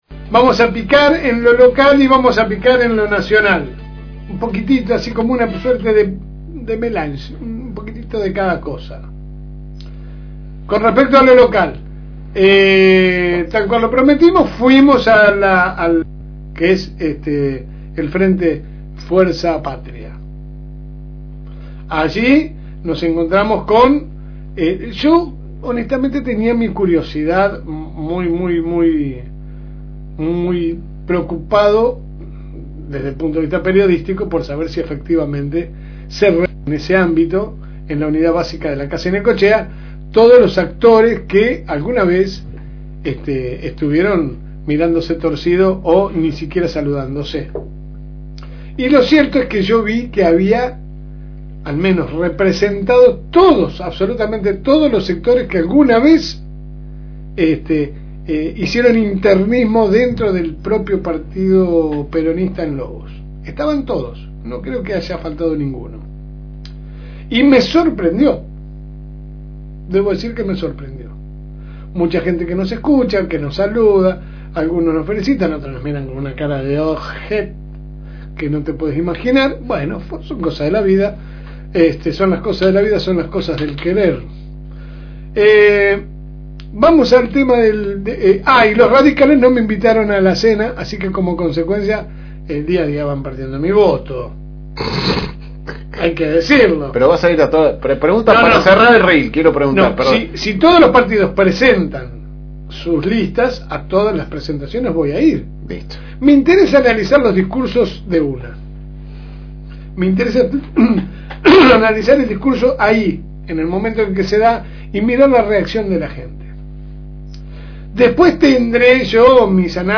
Compartimos con ustedes la editorial de LA SEGUNDA MAÑANA